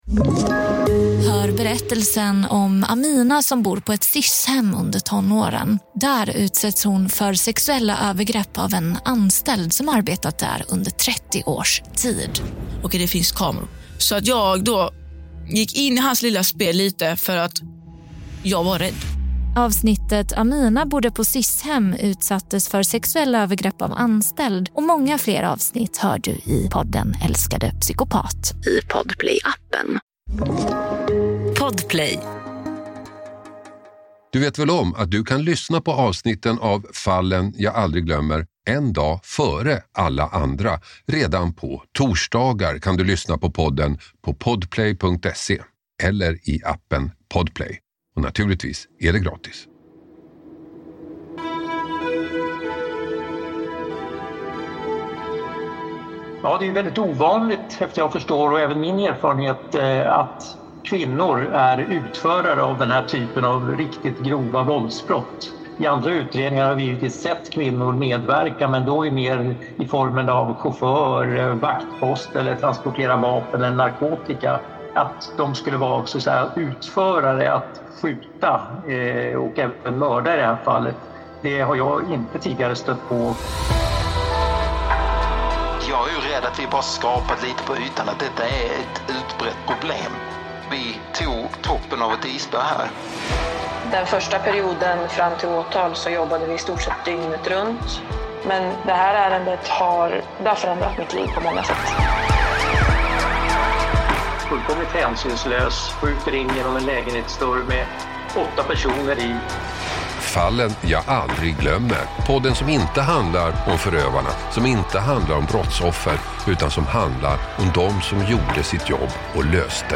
Hasse Aro intervuar